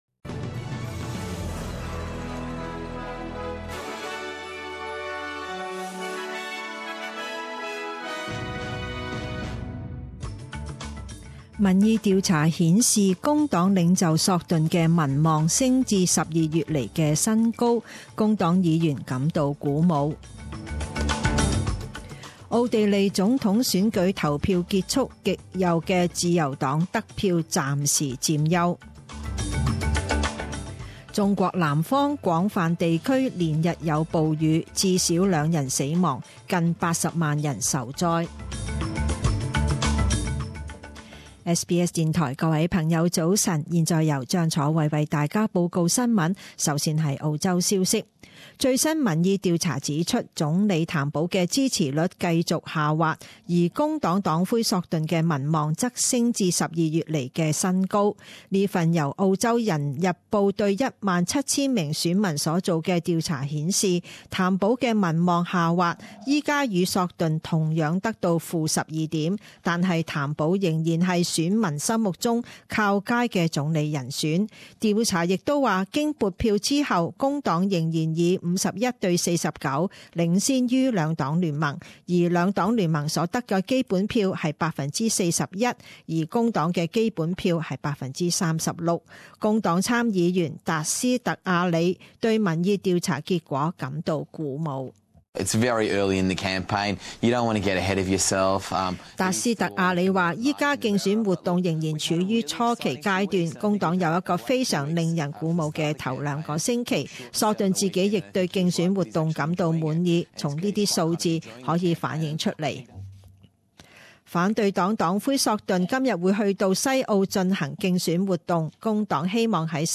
五月二十三日十点钟新闻报导